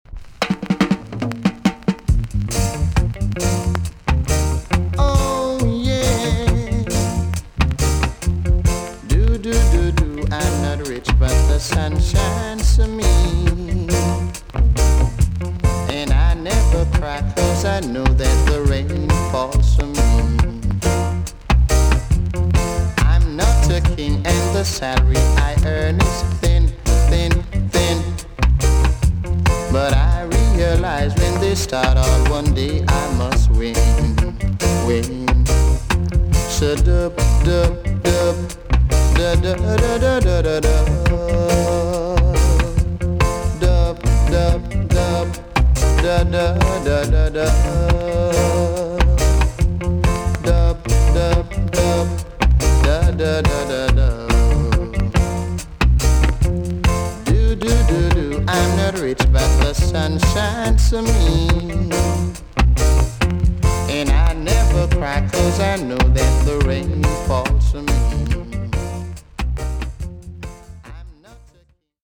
TOP >REGGAE & ROOTS
EX-~VG+ 少し軽いチリノイズがあります。
NICE VOCAL TUNE!!